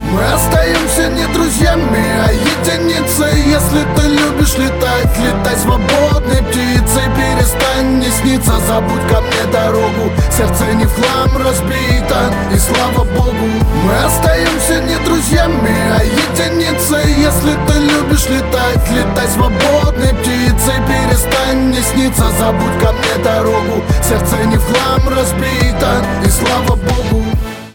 Рэп
Печальный трек про расставание